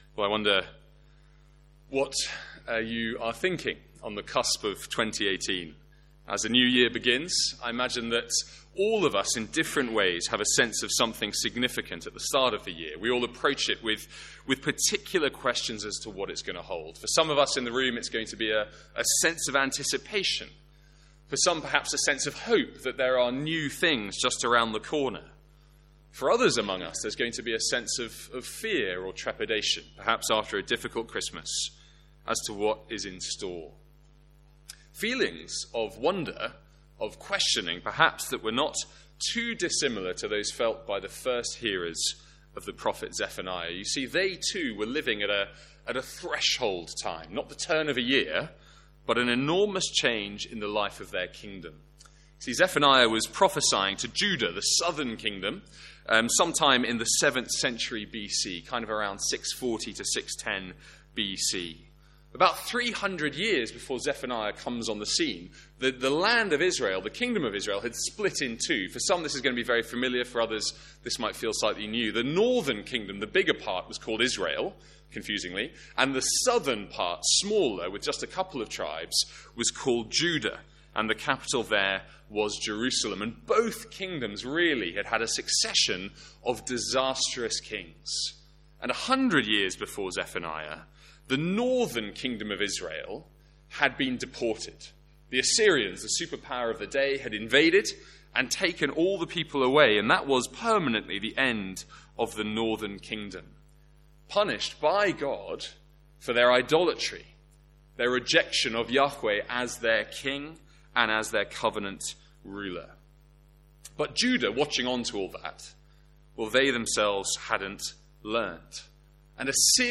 Sermons | St Andrews Free Church
Reading not included in the recording.